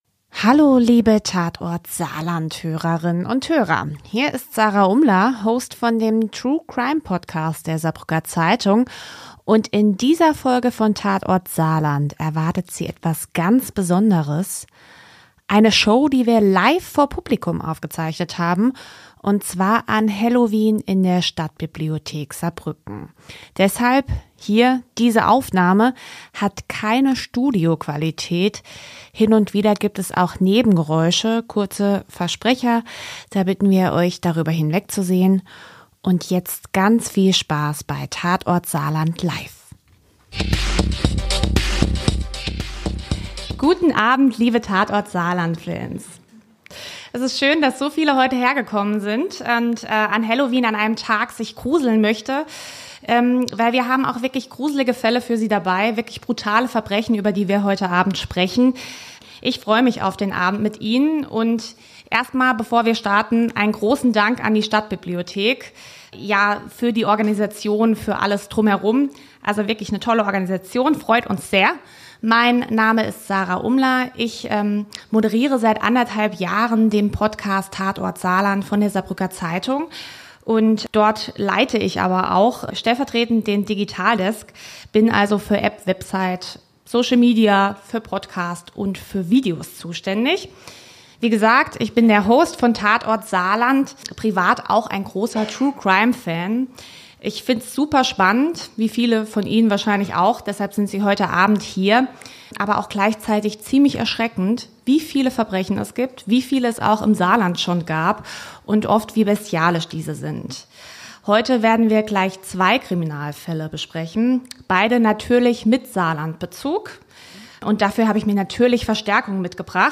Premiere für den True-Crime-Podcast der SZ: „Tatort Saarland“ wurde erstmals live vor Publikum aufgezeichnet. In der Stadtbibliothek Saarbrücken wurden gleich zwei schreckliche Verbrechen besprochen: Brutale Morde in Saarlouis-Roden und ein Serienmörder aus dem Saarland, der in der Ferne tötete.